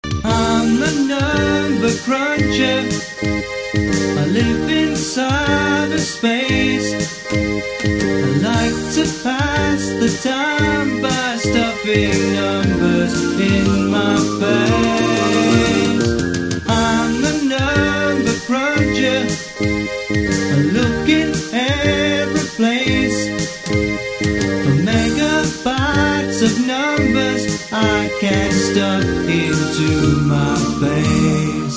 A musical look at computers.